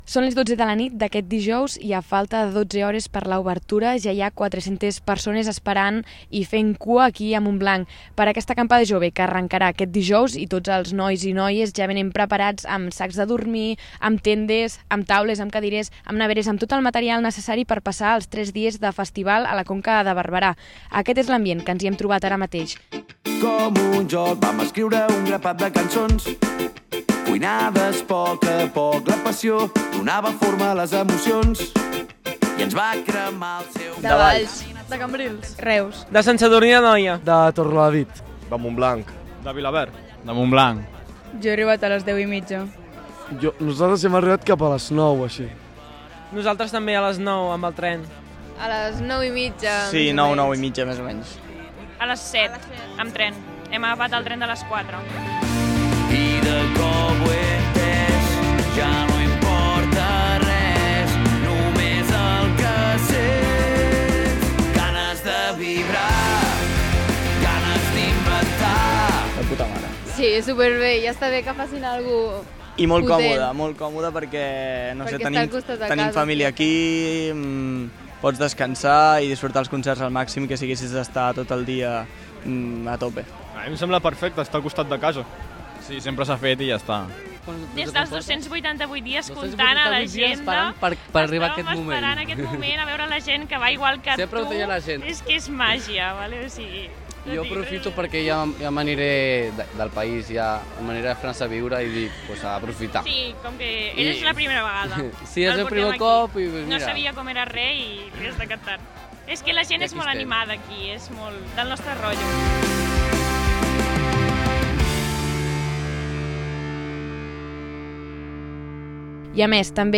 REPORTATGE: La primera nit «improvisada» de l’Acampada Jove
Reportatge-primera-nit-Acampada-Jove.mp3